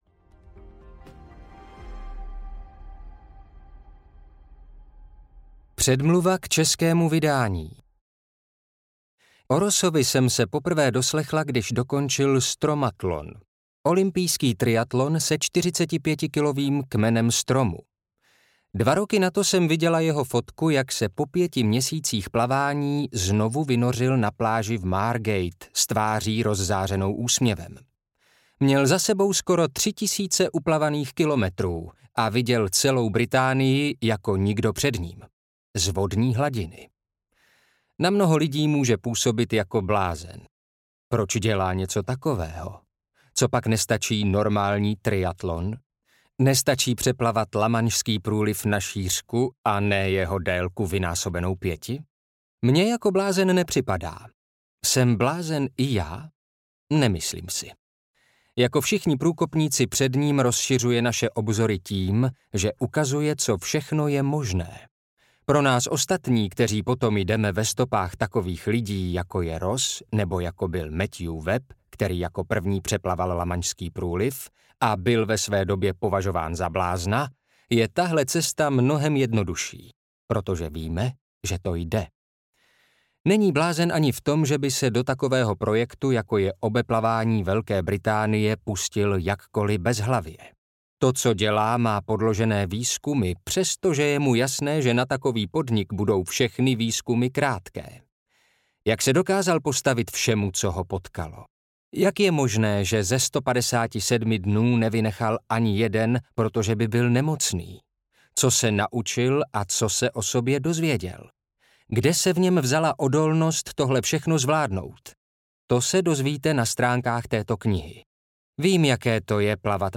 Umění odolnosti audiokniha
Ukázka z knihy